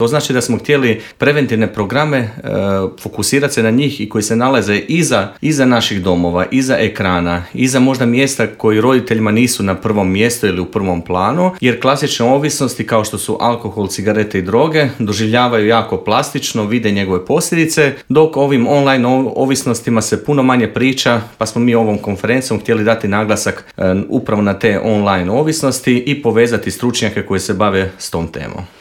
O svemu tome u Intervjuu Media servisa